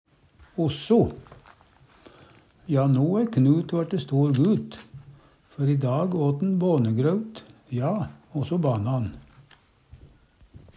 DIALEKTORD PÅ NORMERT NORSK osso også, og, i tillegg, pluss Eksempel på bruk Ja, no æ Knut vørte stor gut, før i dag åt'n bånegraut, ja, osso banna'n. Hør på dette ordet Ordklasse: Adverb Attende til søk